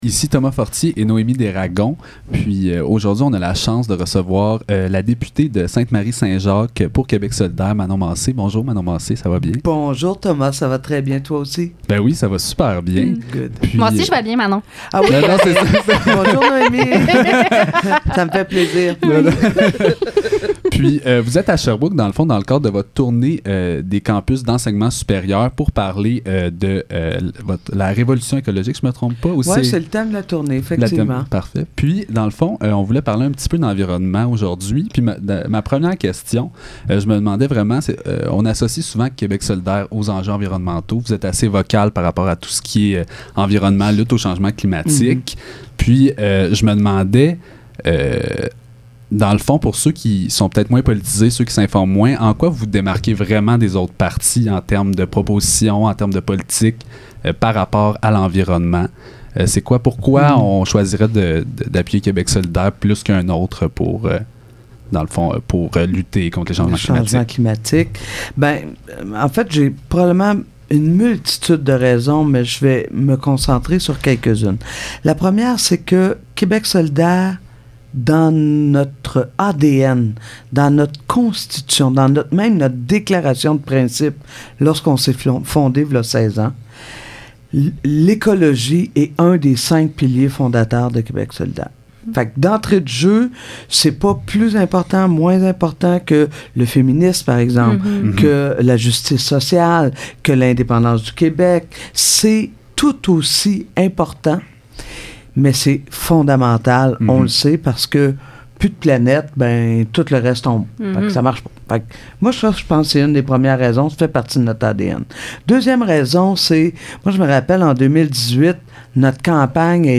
Le Sept - Discussion avec Mme Manon Massé de Québec Solidaire à propos sa visite en Estrie - 29 novembre 2021
Entrevue-Manon-Mass-1.mp3